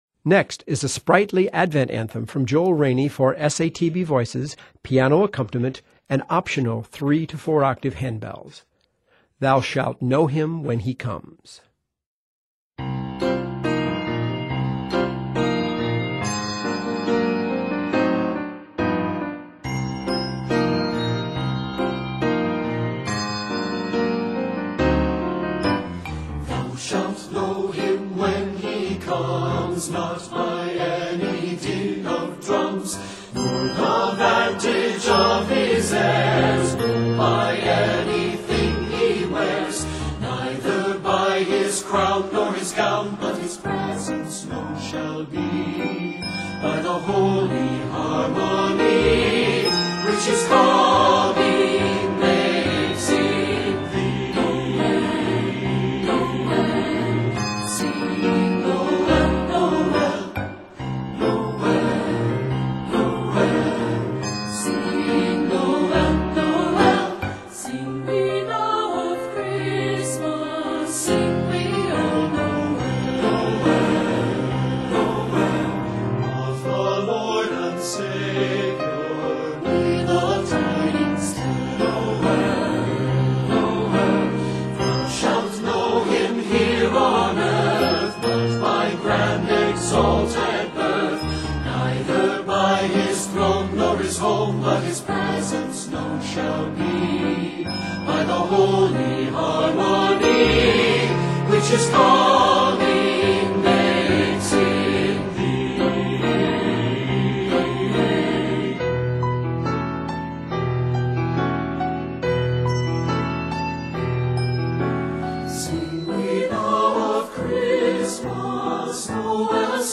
sprightly Advent selection